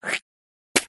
Звуки плевка
Простенький вариант